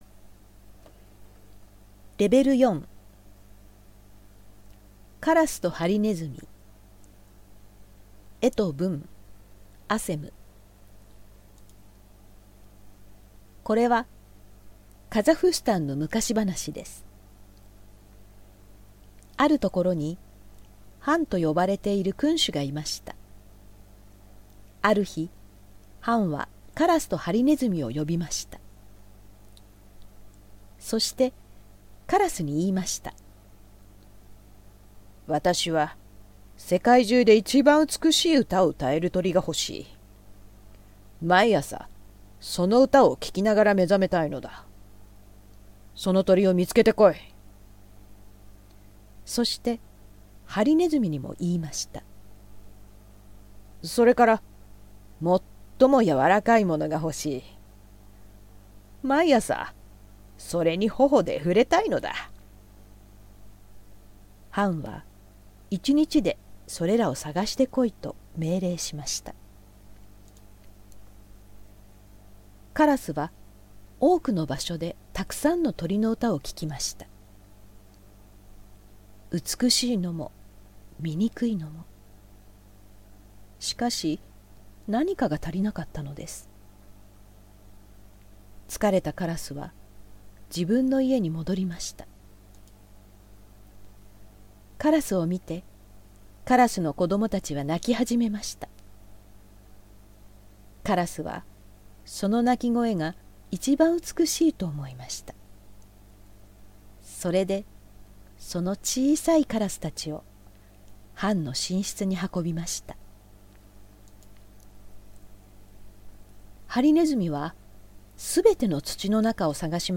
朗読音声付き